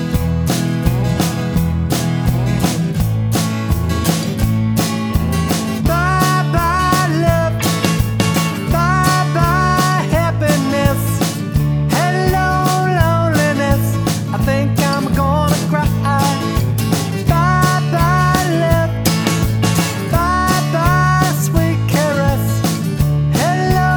No Harmony Pop (1950s) 2:24 Buy £1.50